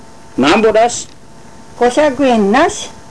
この町のことばをお聴きいただけます